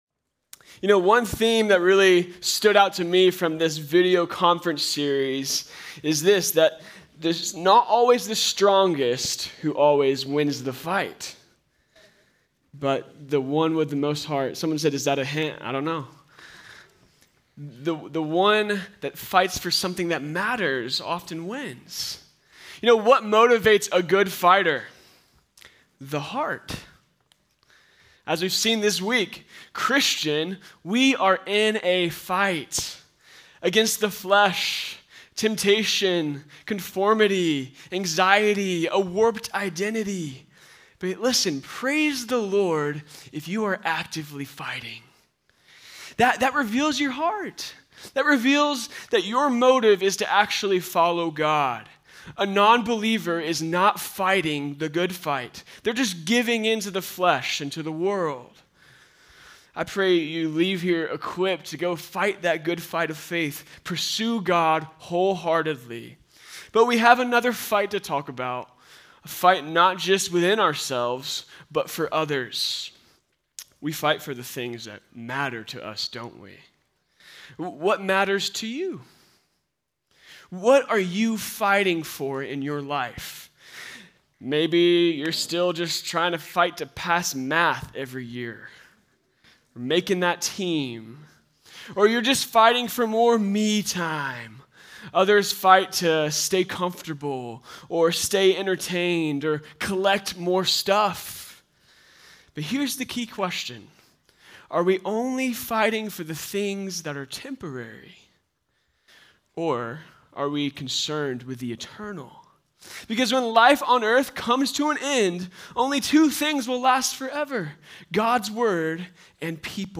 Home » Sermons » The Fight for the Lost
Conference: Youth Conference